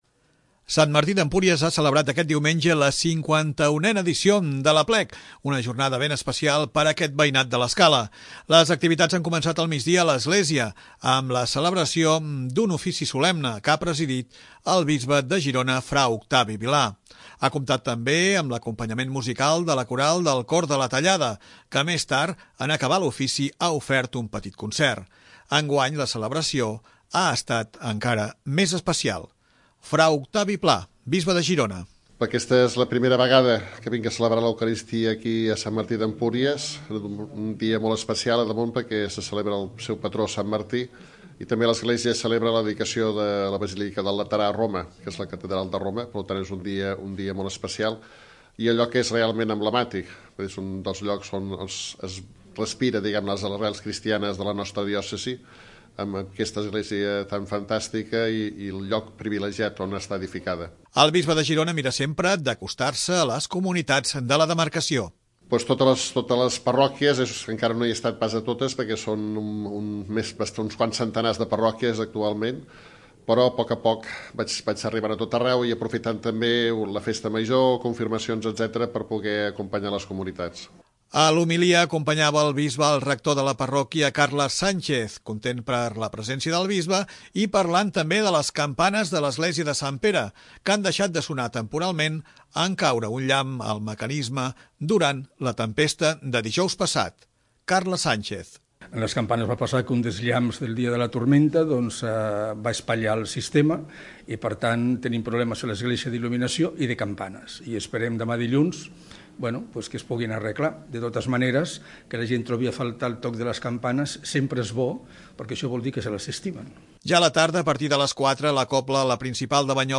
S'ha celebrat una missa solemne amb presència del Bisbe de Girona, Fra Octavi Vilà, una audició de sardanes i no ha faltat la parada del CER de venda de castanyes.
Les activitats han començat al migdia a l'església,  amb la celebració un ofici solemne, que ha presidit pel Bisbe de Girona, fra Octavi Vilà.
Ha comptat també  amb acompanyament musical de la coral del Cor de la Tallada,  que més tard, en acabar l'ofici, ha ofert un petit concert.
Ja a la tarda, a partir de les 4, la cobla La Principal de Banyoles era l'encarregada d'una audició de sardanes que es celebrava a la Plaça Major de Sant Martí.